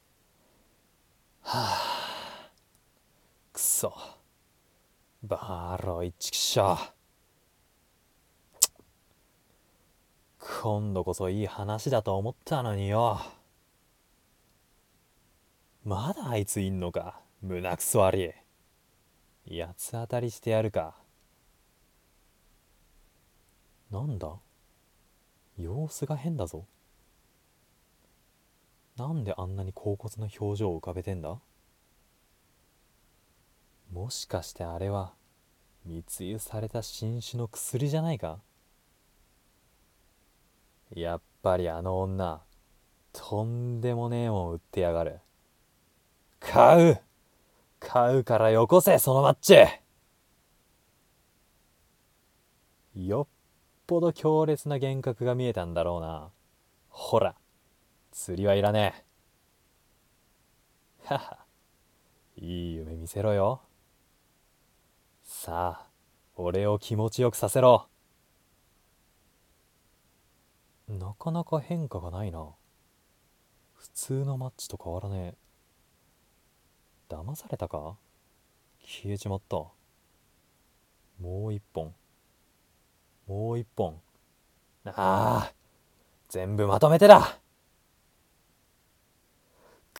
コラボ声劇2